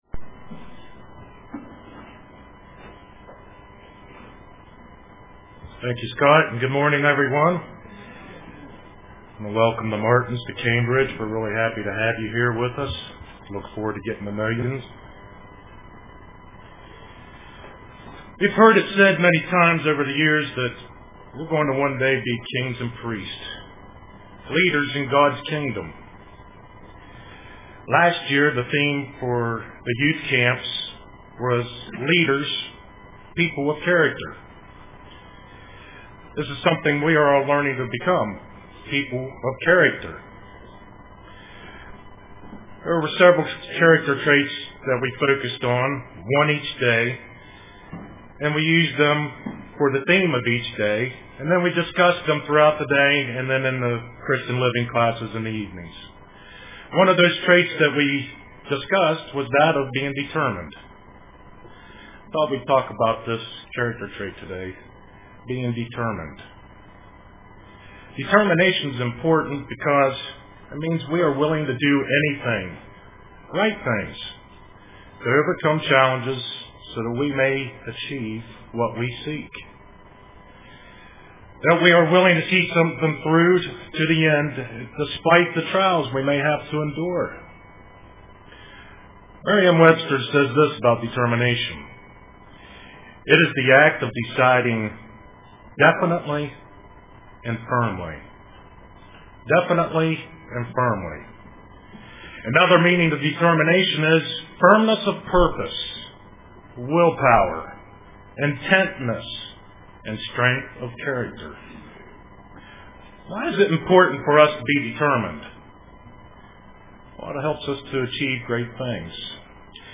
Print Determination UCG Sermon Studying the bible?